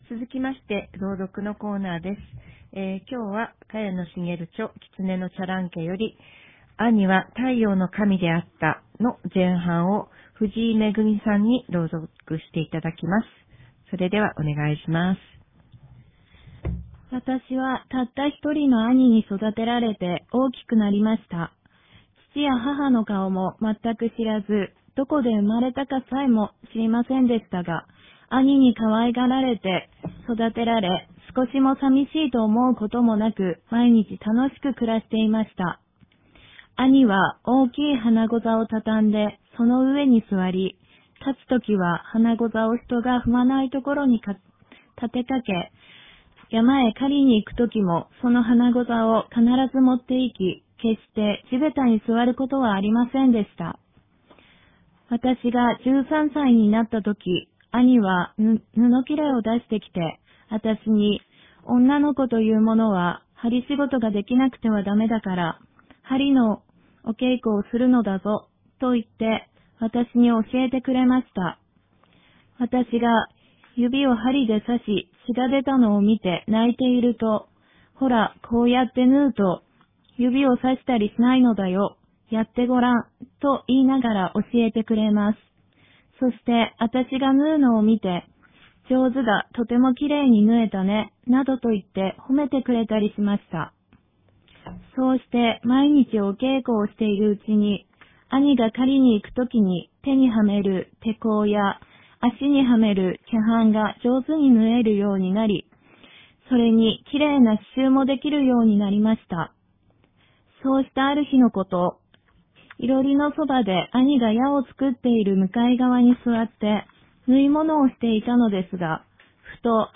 ■朗読（１３）：「兄は太陽の神であった」前半（萱野茂 著『キツネのチャランケ』（小峰書店）より）